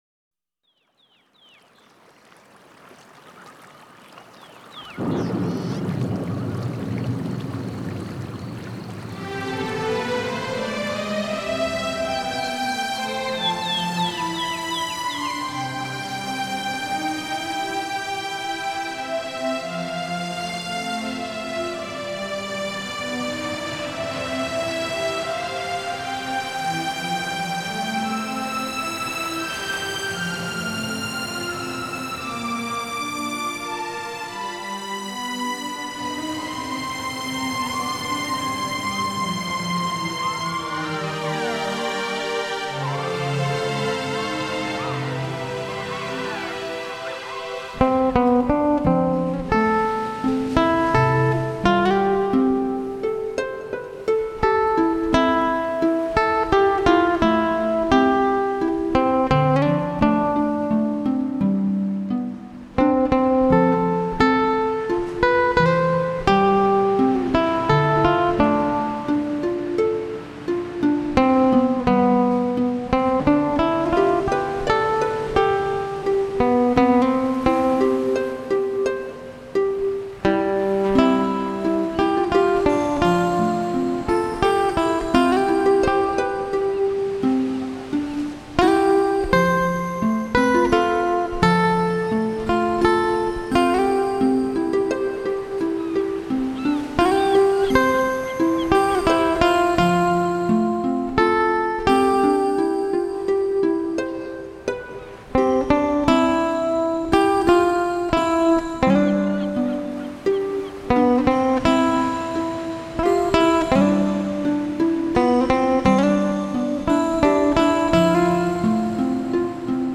轻音乐
鳥啼 撲浪的聲音及電吉它